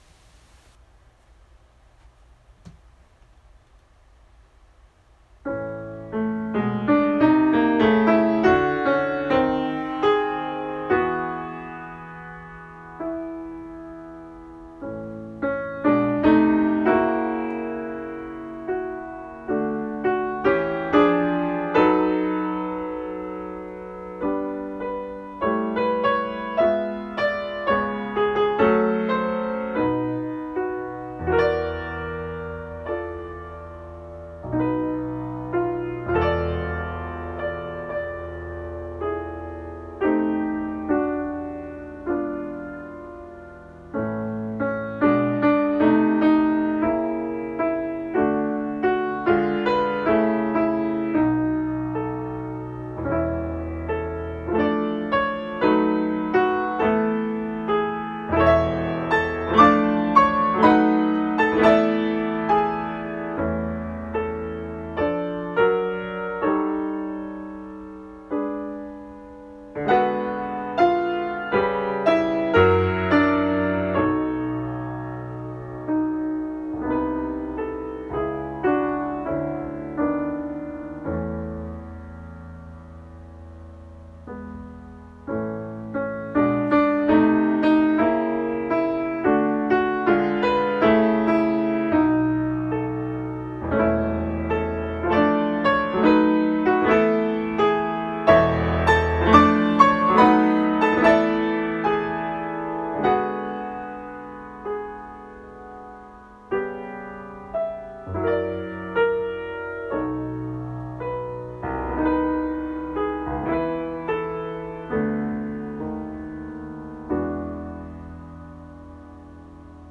A sampling of my solo and chamber music recordings:
Laue Sommernacht by Alma Mahler (solo piano version)